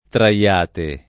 traL#te], traggano [tr#ggano] — fut. trarrò [trarr0+]; imperf. ind. traevo [tra%vo]; pass. rem. trassi [